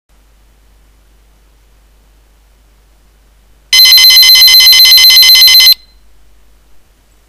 ○本鈴